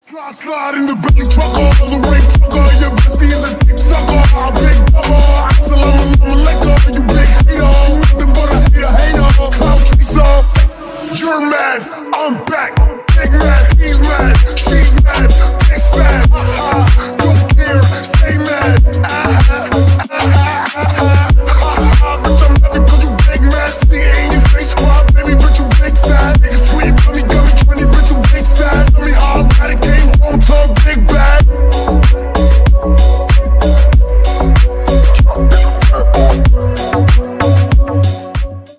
Ремикс # Рэп и Хип Хоп
громкие